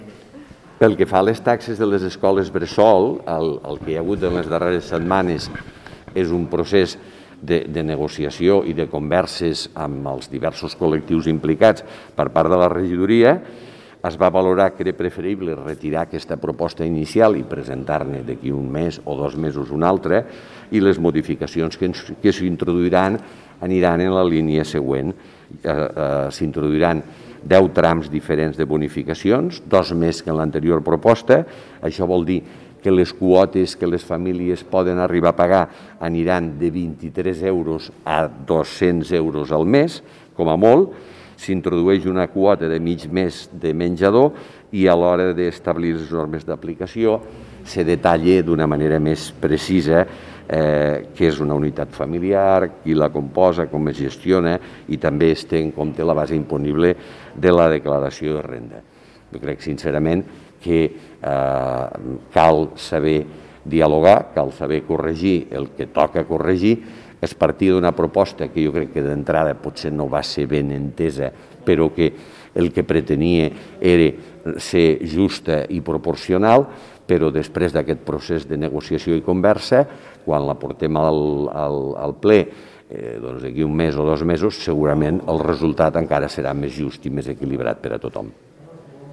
tall-de-veu-de-lalcalde-sobre-la-modificacio-de-la-taxa-per-serveis-educatius